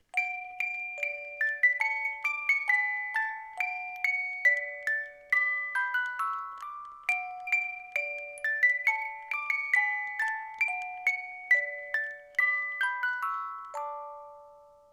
Spieluhr mit dem Kirchenlied: Ein feste Burg ist unser Gott, deluxe edition
der Korpus besteht aus Bambus
die Spieluhr besitzt ein Qualitätsspielwerk mit 18 Zungen
Der Ton dieser Spieluhren ist klar, warm und obertonreich und wird durch Kurbeln eines Qualitätsspielwerks erzeugt.